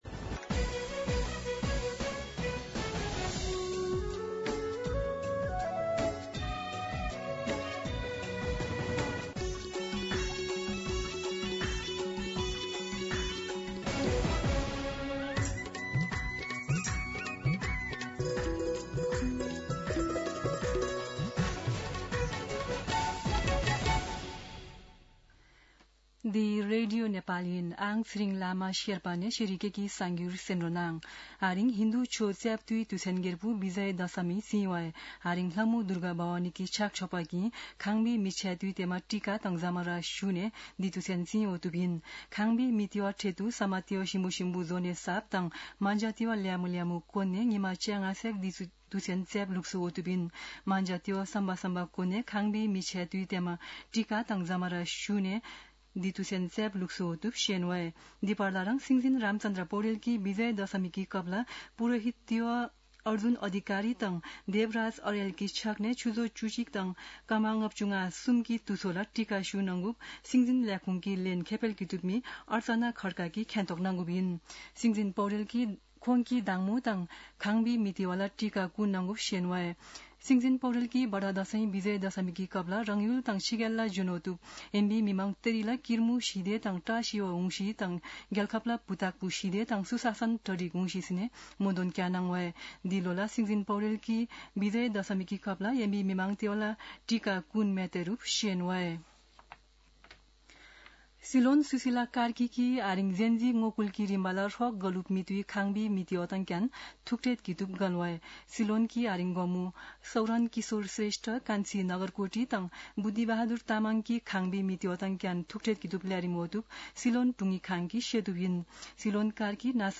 An online outlet of Nepal's national radio broadcaster
शेर्पा भाषाको समाचार : १६ असोज , २०८२
sherpa-News-1.mp3